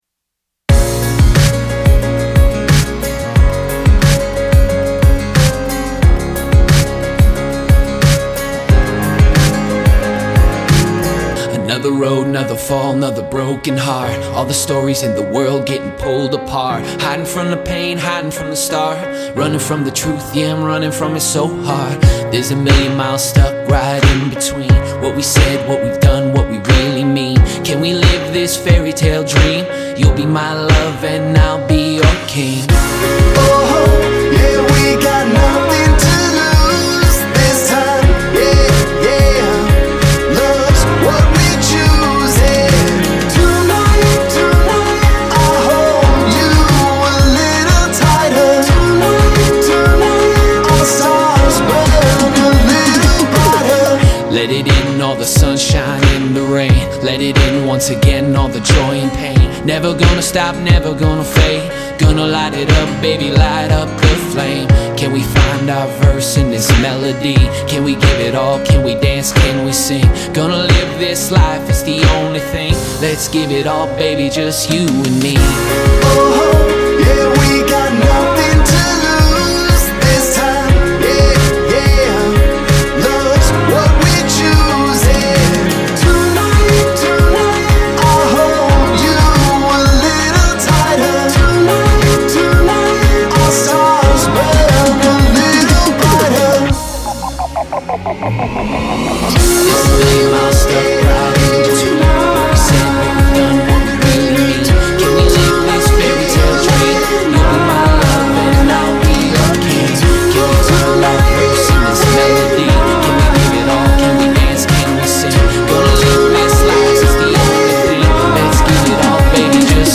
Instrumental drafts